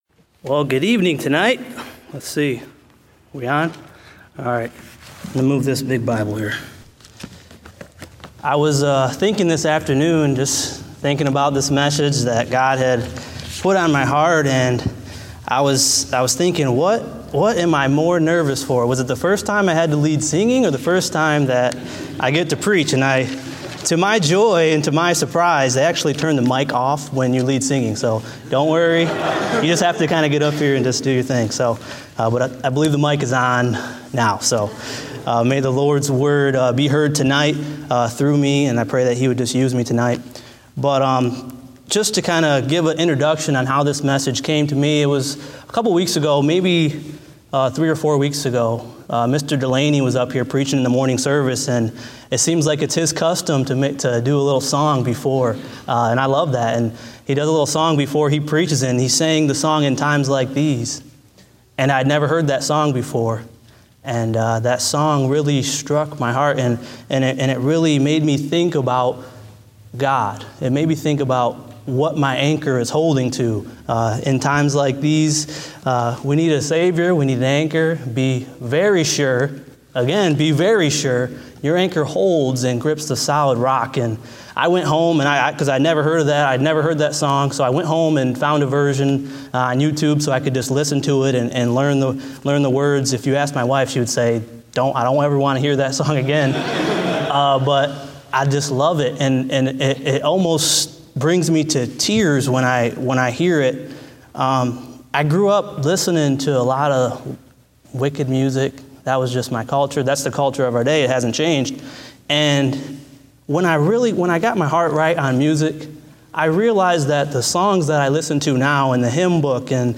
Date: May 8, 2016 (Evening Service)